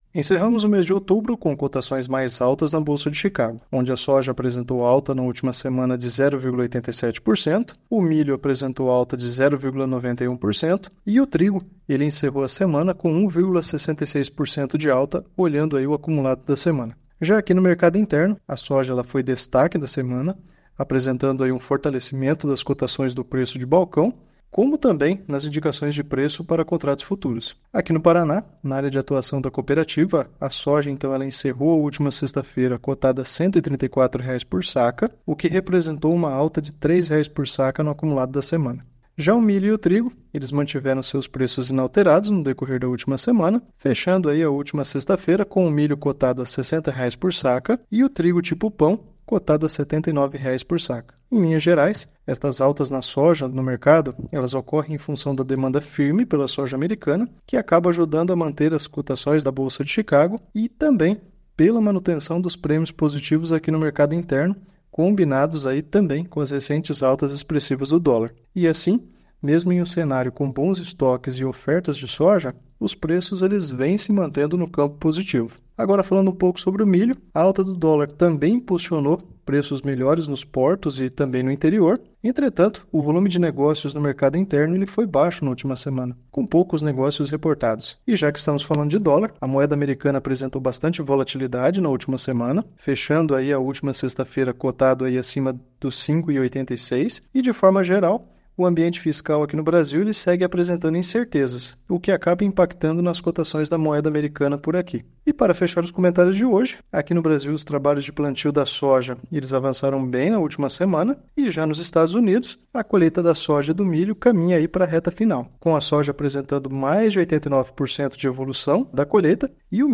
ÁUDIO – Comentário mercado agropecuário 04/11/2024